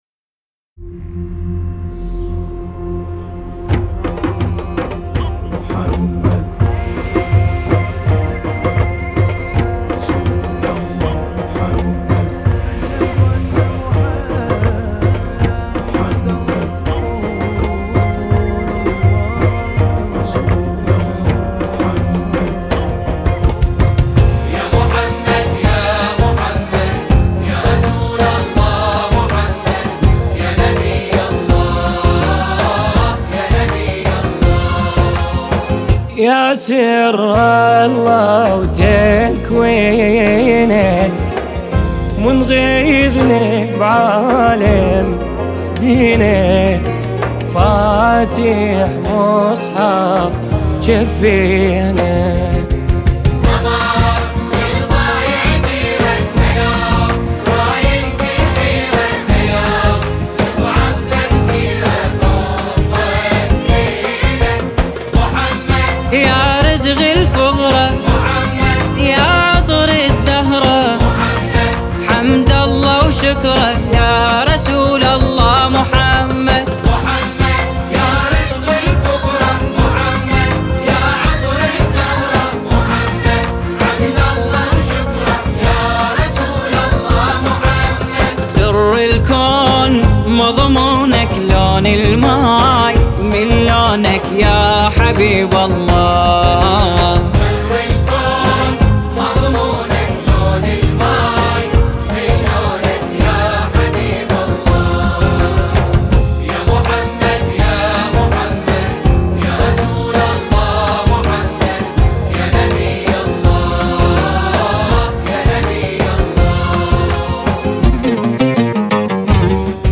نماهنگ زیبای
ویژه میلاد مسعود پیامبر اکرم صلی الله علیه و آله و سلم.